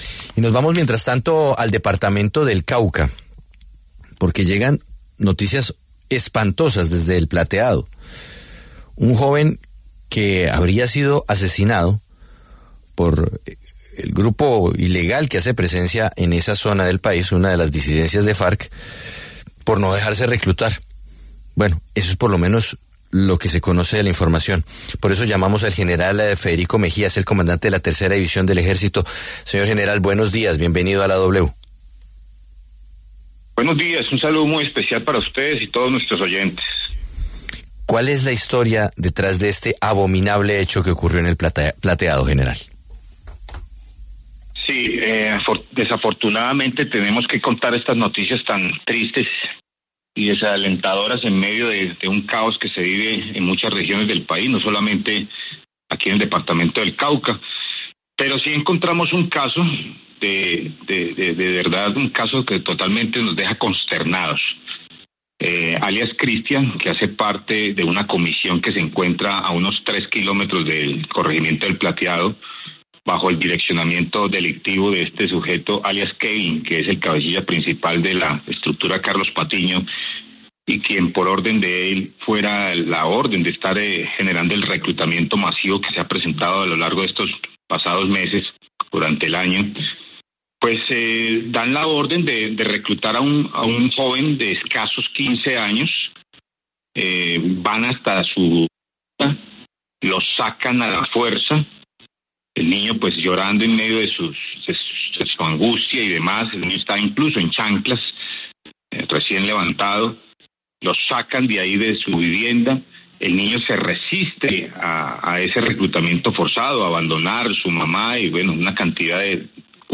En diálogo con La W, el general Federico aseveró que lograron evidenciar una despiadada forma de reclutar de los grupos armados al margen de la ley, quienes están perfilando a los menores para reclutarlos.